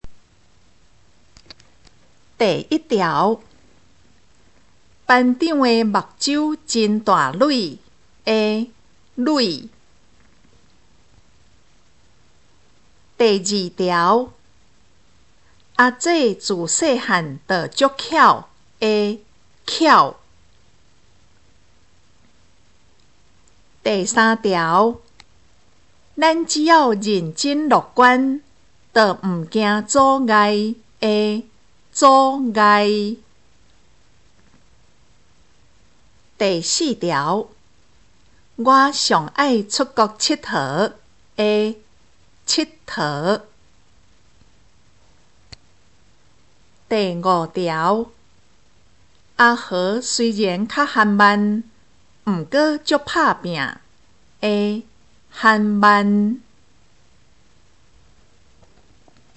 【國中閩南語4】每課評量(2)聽力測驗mp3